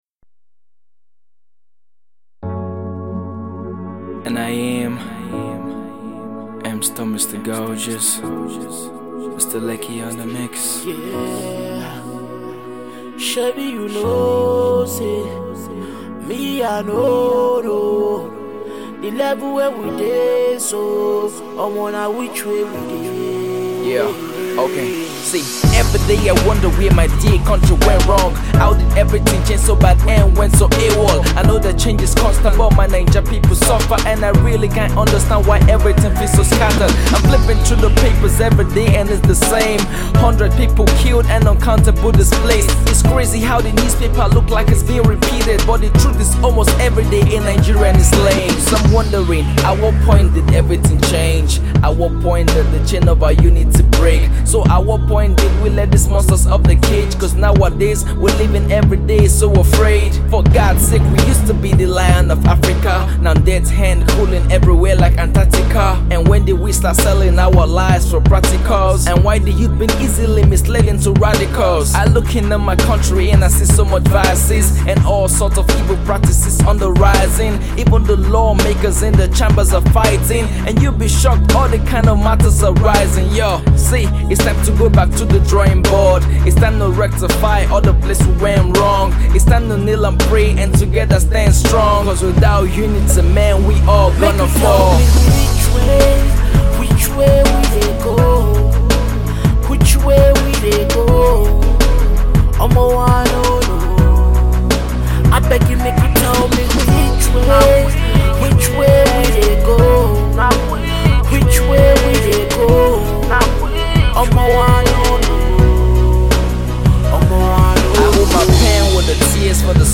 heart touching rap single